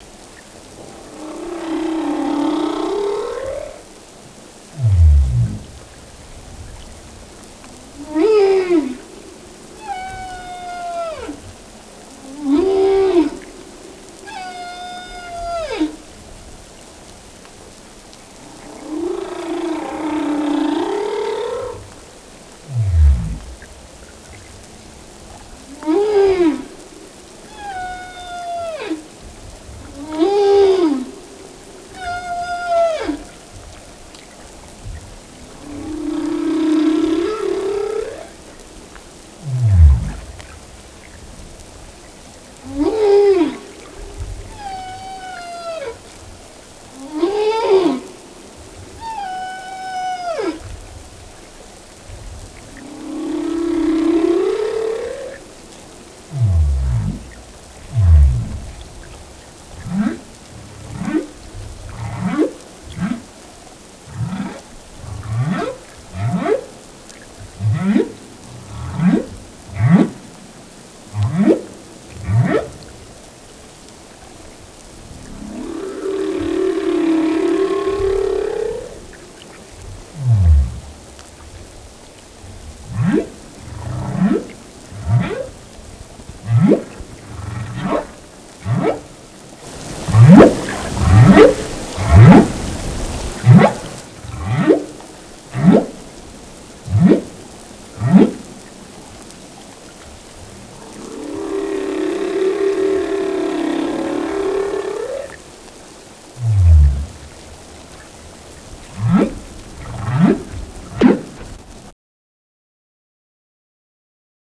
Los cantos de la ballena jorobada están entre los más complejos del reino animal.
Cantos de ballenas jorobadas, grabadas en Hawai.
La estructura del canto de la ballena jorobada es repetitiva y rígida. Las ballenas repiten frases únicas constituidas por segmentos cortos y largos que forman un canto.